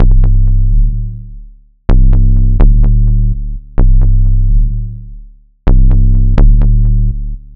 • Techno Sub Bass Resonator.wav
Techno_Sub_Bass_Resonator_1__bAc.wav